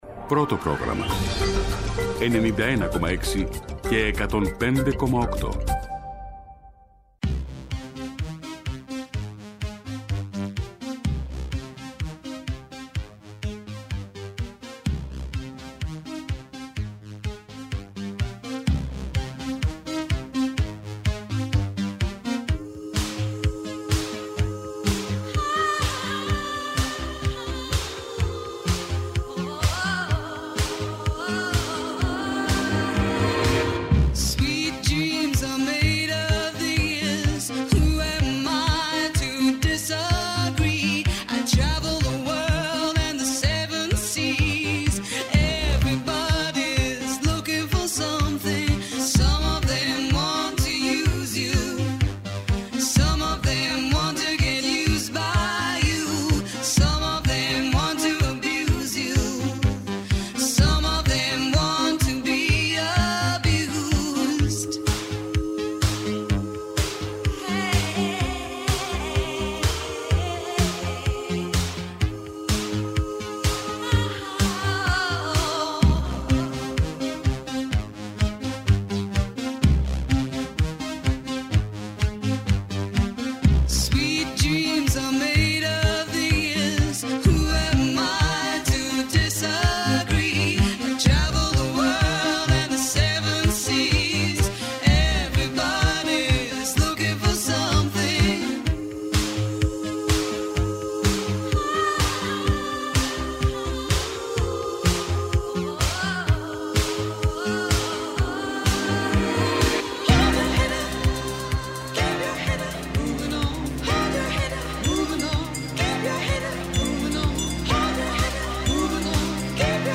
Καλεσμένοι σήμερα: Ο Γιάνης Βαρουφάκης, γραμματέας του ΜέΡΑ25.
Συνεντεύξεις